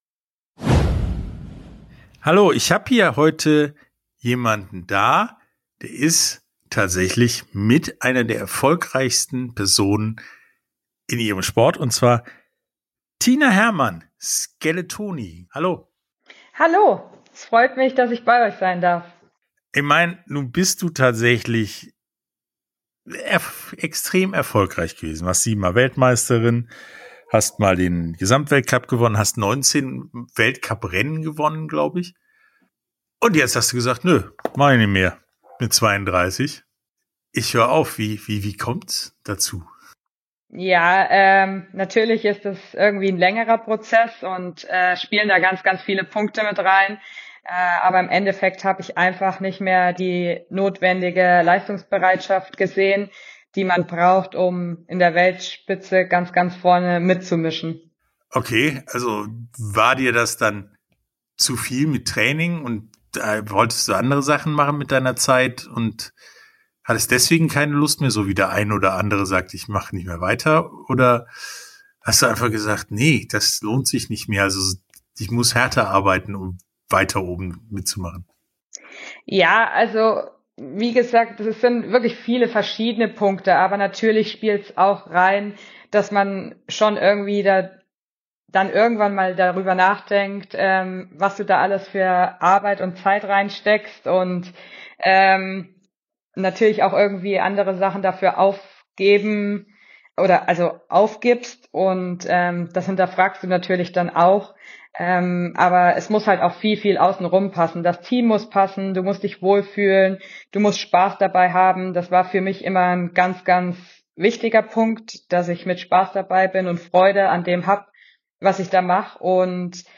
Sportstunde - Interview komplett Interview komplett Tina Herrmann, Skeleton ~ Sportstunde - Interviews in voller Länge Podcast